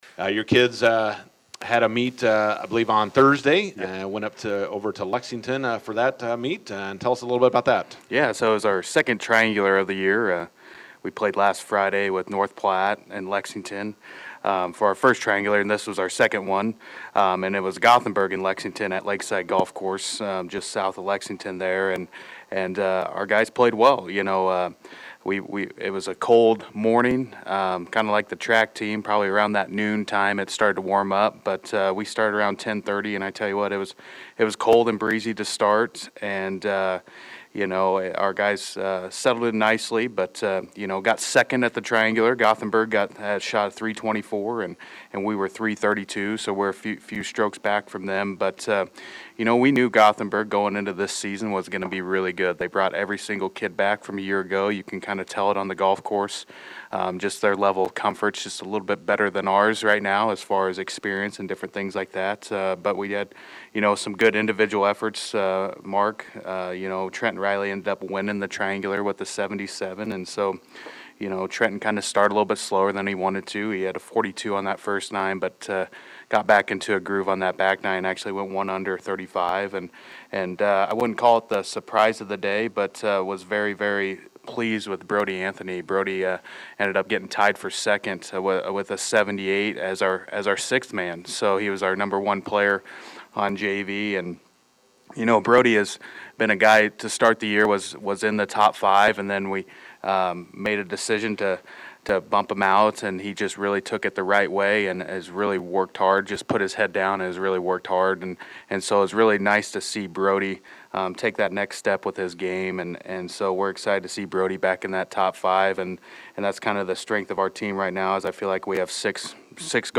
INTERVIEW: Bison boys golf win second straight dual meet, prepare for first home meet Tuesday and first big invite in Kearney on Thursday.